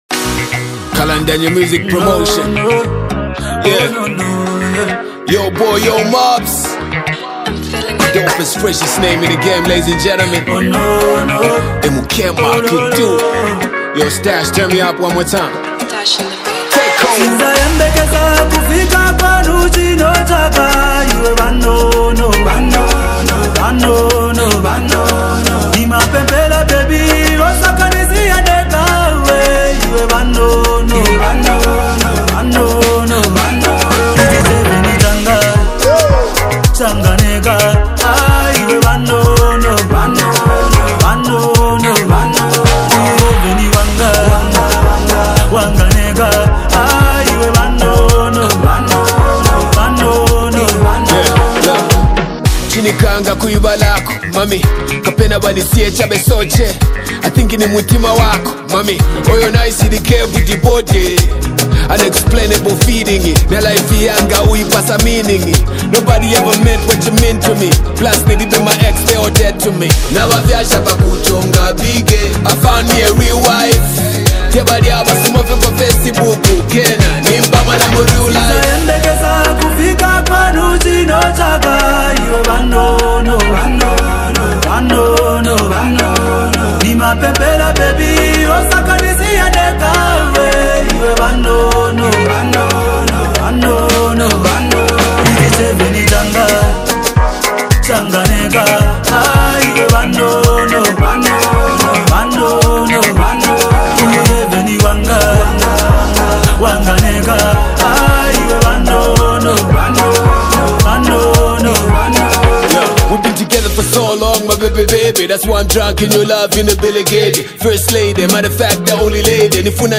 popular Zambian song
rap skills
melodic vocals